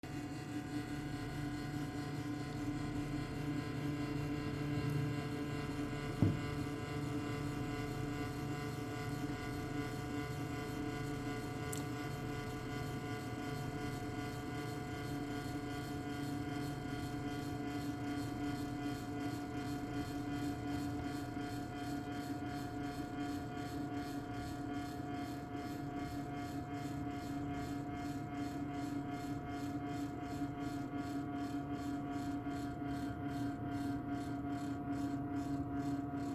Nowa karta graficzna i bzyczenie (głośny dźwięk)
Te trzaski to prawdopodobnie cewka. Jednak na moje ucho wentylatory też działają fatalnie.
No wentylatory nie brzmią zachęcająco jak i te trzaski przy uruchamianiu.